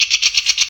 spider.ogg